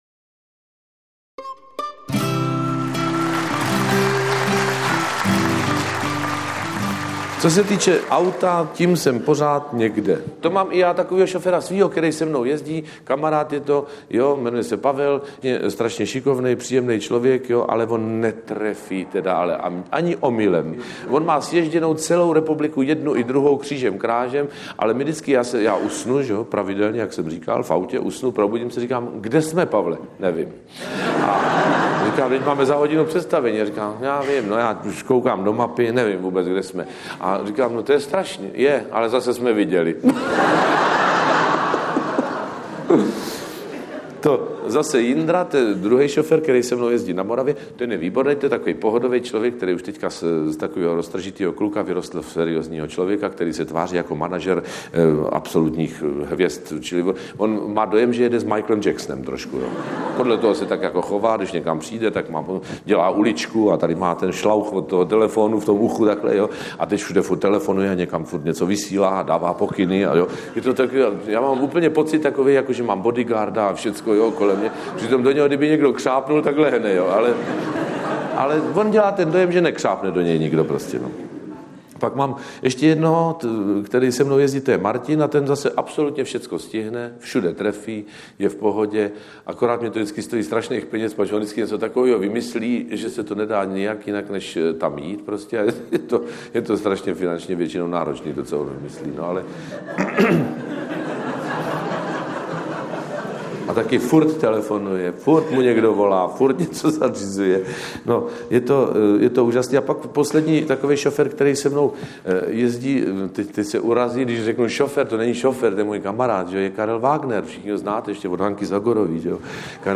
Veľké dieťa - Ivan Tuli Vojtek spolu s country skupinou vás dobre zabaví na cestách aj necestách.